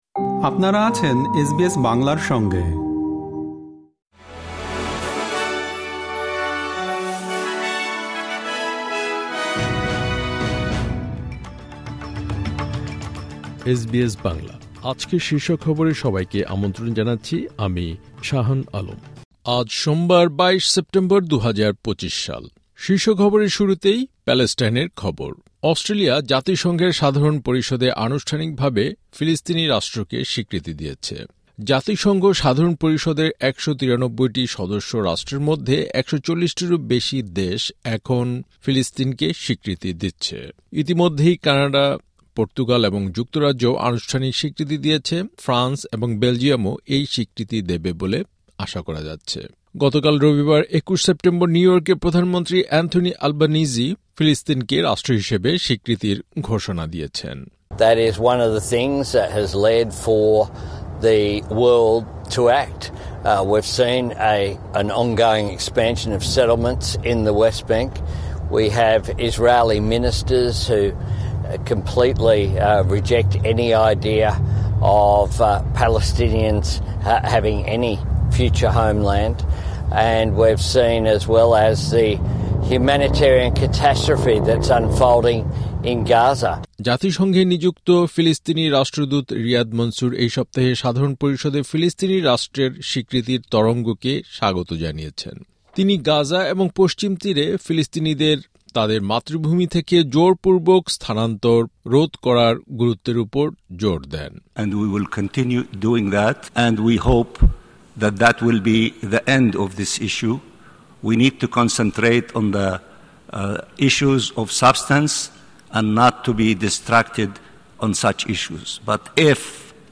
এসবিএস বাংলা শীর্ষ খবর: ২২ সেপ্টেম্বর, ২০২৫